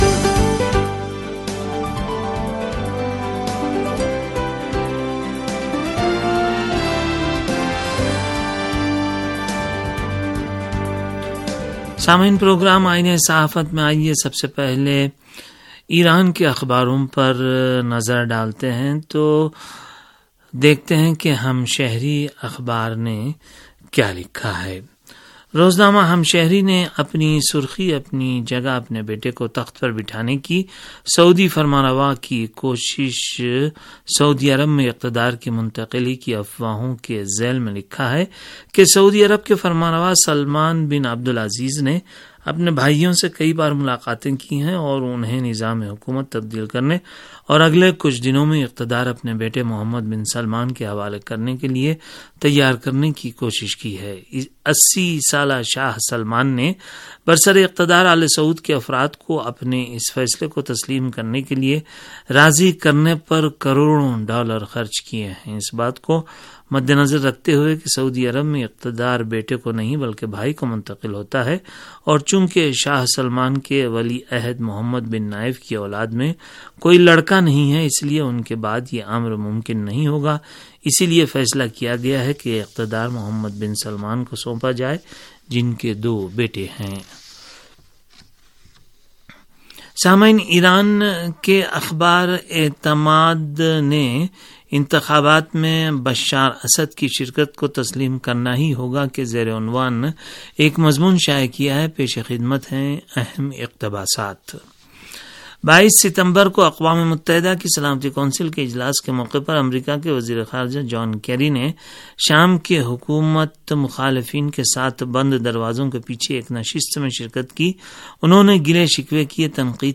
ریڈیو تہران کا اخبارات کے جائزے پر مبنی پروگرام